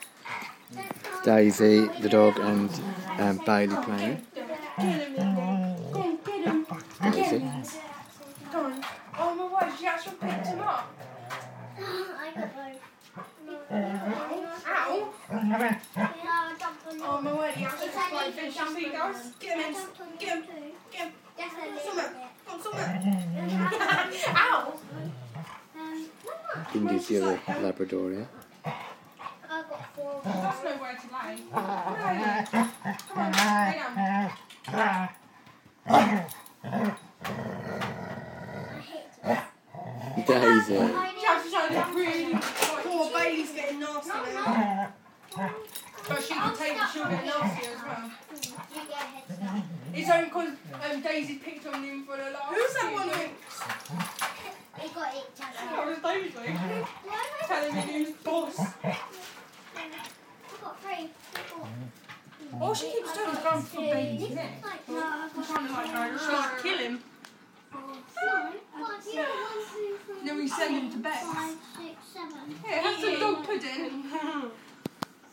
Dogs playing